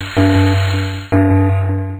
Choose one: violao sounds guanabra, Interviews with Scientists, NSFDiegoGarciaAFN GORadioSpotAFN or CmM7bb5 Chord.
violao sounds guanabra